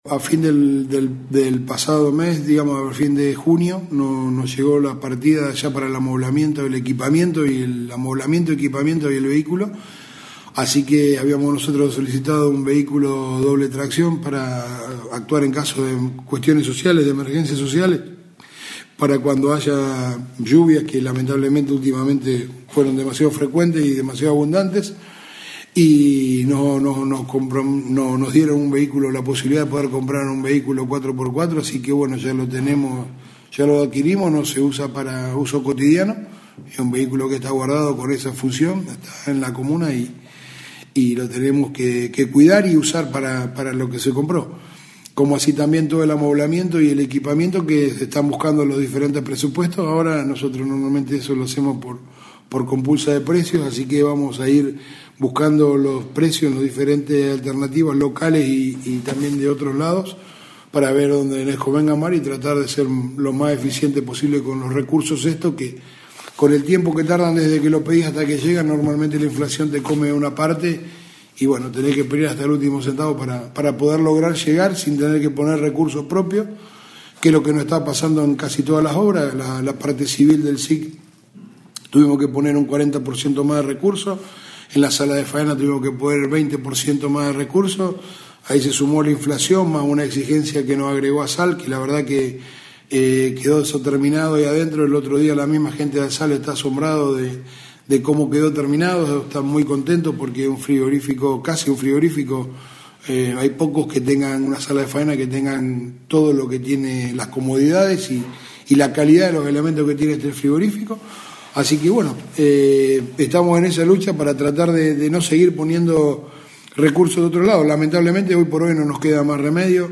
En diálogo con Radio EME, hizo referencia a la futura sucursal de Banco Nación, la habilitación de la sala de faena, la nueva unidad móvil y el equipamiento del para el CIC, el comienzo de la obra de la ciclovía, el estado de las calles y su reparación, la necesidad de mejoras en el basural, y los trabajos que se hacen para tratar de evitar volver a sufrir inconvenientes en caso de lluvias.